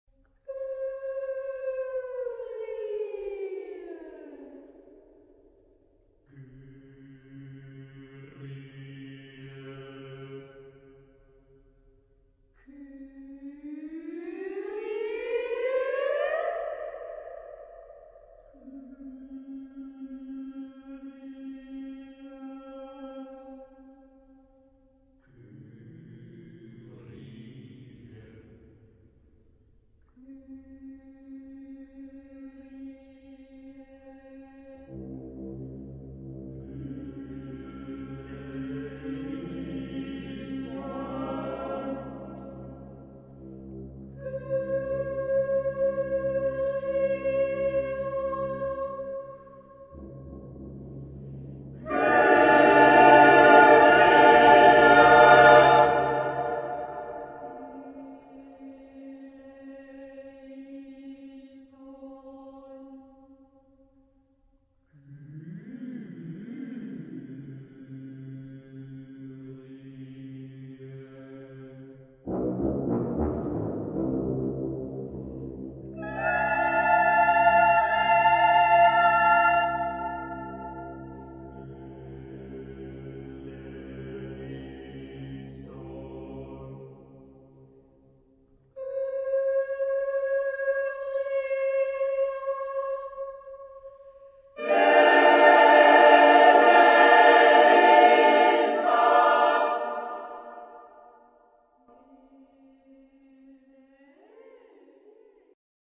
Género/Estilo/Forma: opera sagrado
Carácter de la pieza : arcaico ; moderno ; expresivo
Tonalidad : tonal ; atonal